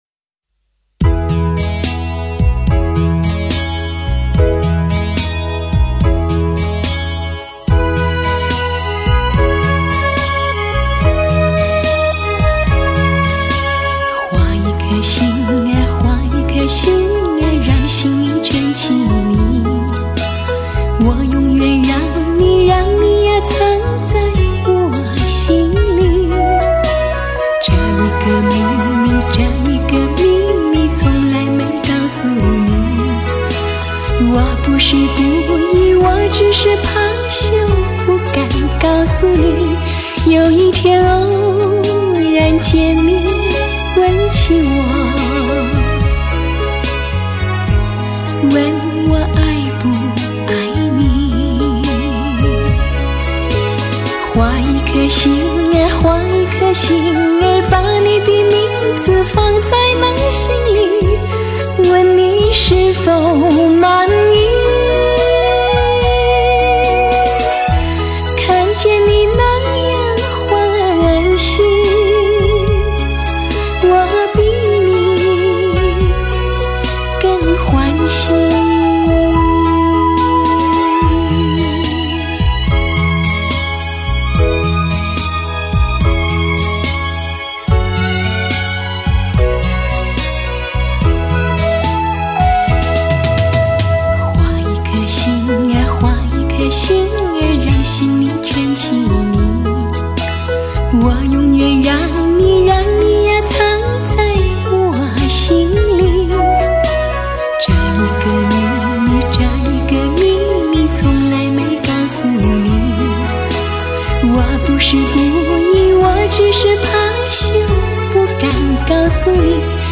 纯纯甜美的歌声，天籁般的声音，崭新而唯美的乐章，
顶级音乐制作人专业的发烧女声天碟。
纯纯甜美的歌声，如水般的笑容，
唯美的发烧天籁，营造一个透明的梦境。
绝对经典，同期录音，留存纯真品质。